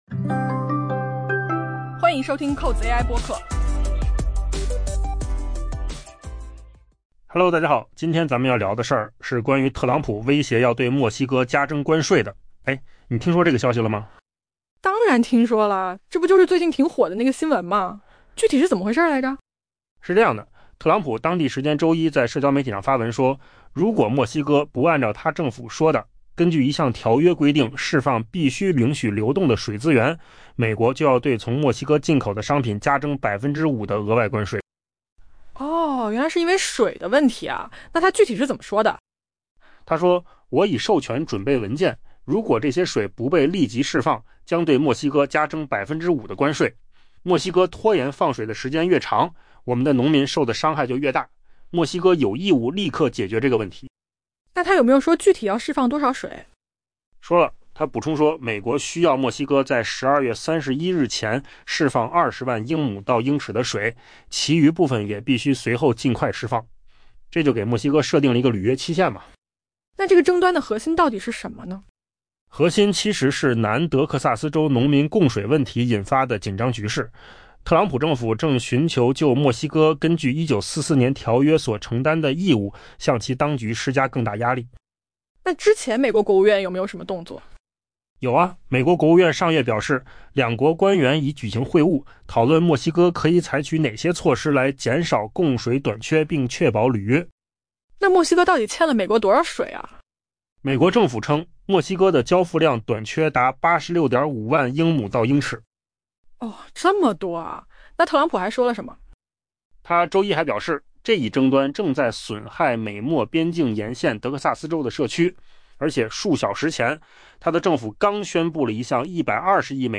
AI 播客：换个方式听新闻 下载 mp3 音频由扣子空间生成 美国总统特朗普威胁称， 如果墨西哥不按照其政府所言，根据一项条约规定释放必须允许流动的水资源，美国将对从墨西哥进口的商品加征 5% 的额外关税，此举升级了与这一主要贸易伙伴的争端。